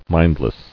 [mind·less]